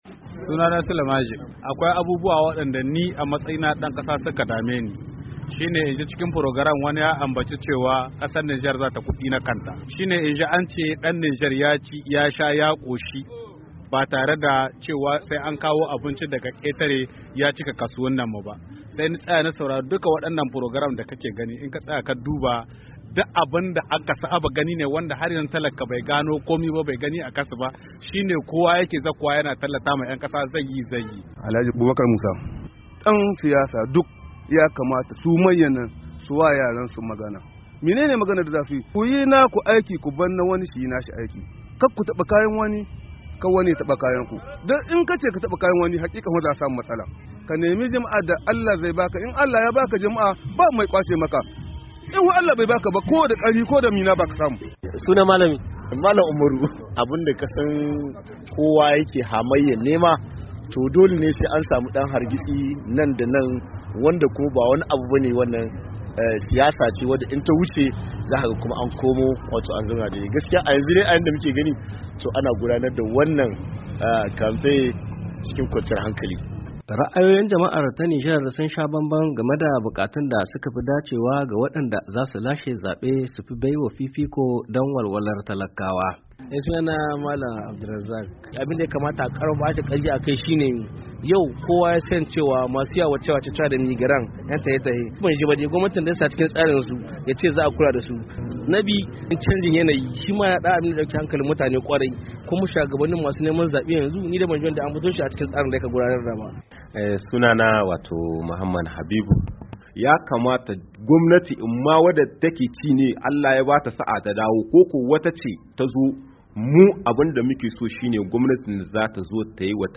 Muryar Amurka ta zagaya ta ji ra'ayoyin wasu 'yan Nijar akan abubuwan da suke son duk wanda ya zama shugaban kasa ya mayar da hankalinsa akai